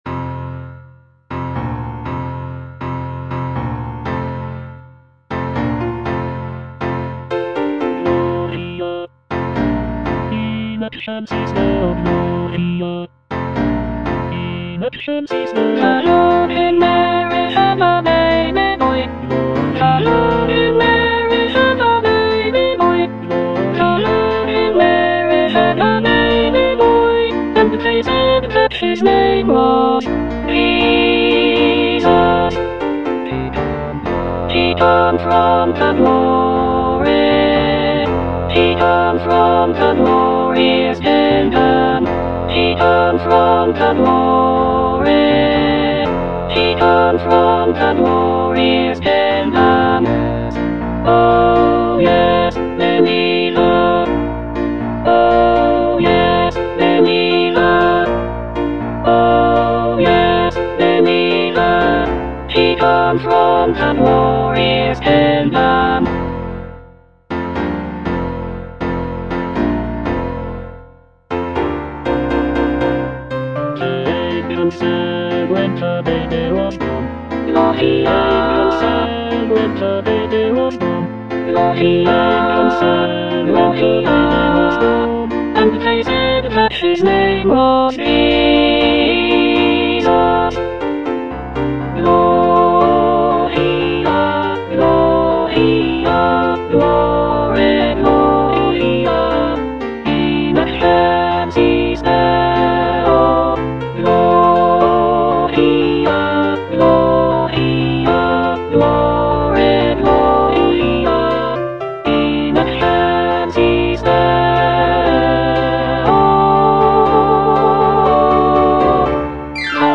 Alto (Emphasised voice and other voices)
" set to a lively calypso rhythm.
incorporating Caribbean influences and infectious rhythms.